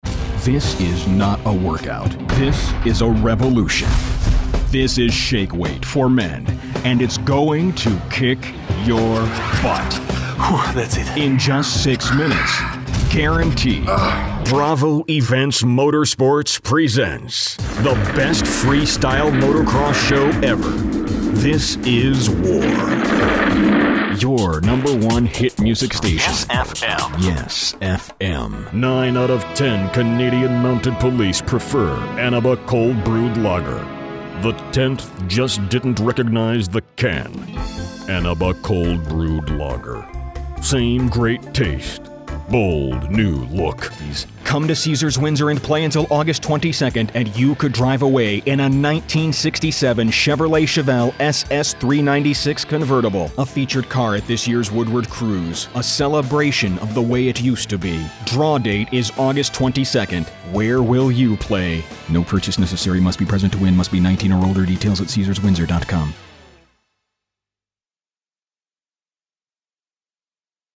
HARD SELL! (sorry for shouting)